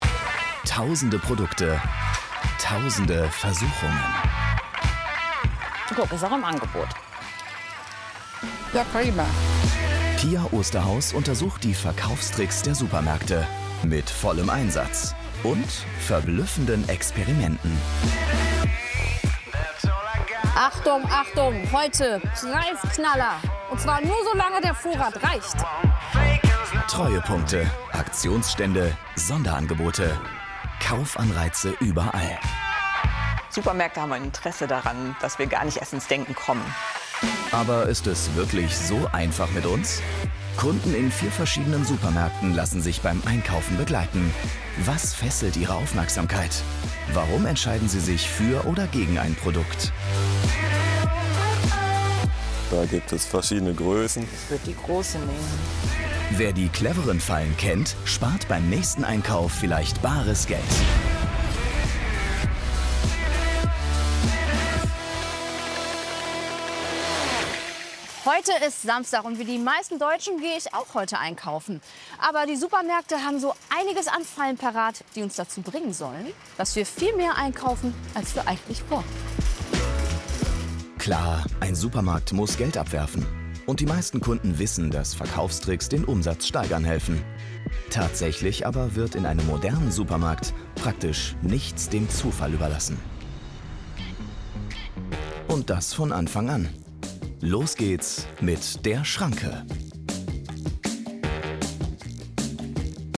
sehr variabel, markant, hell, fein, zart, plakativ
Off, Scene, Audiobook (Hörbuch)